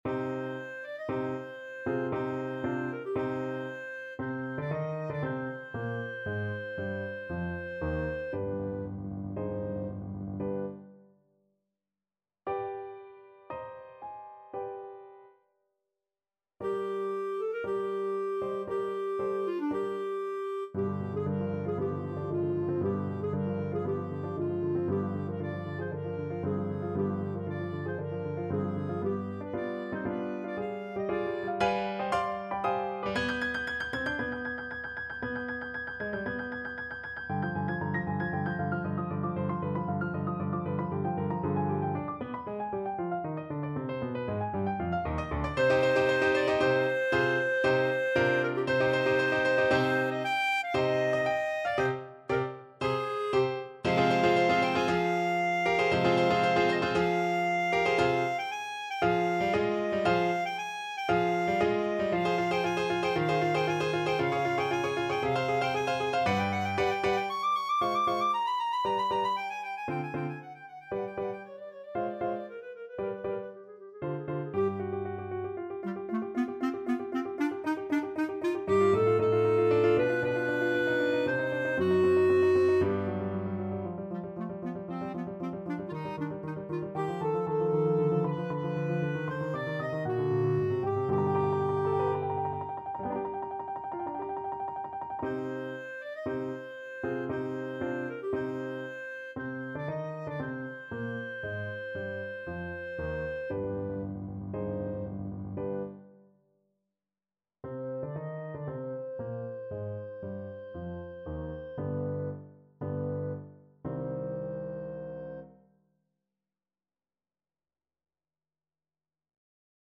Clarinet
C minor (Sounding Pitch) D minor (Clarinet in Bb) (View more C minor Music for Clarinet )
4/4 (View more 4/4 Music)
Allegro =116 (View more music marked Allegro)
dvorak_cello_concerto_1st_main_CL.mp3